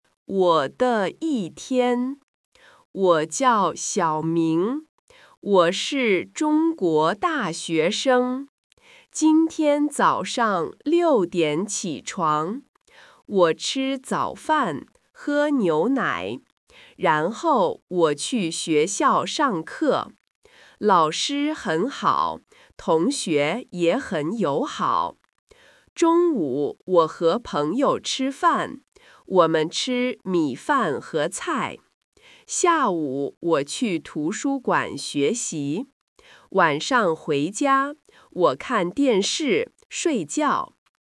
Take it easy and start with the slower version.
《我的一天》-Slow.mp3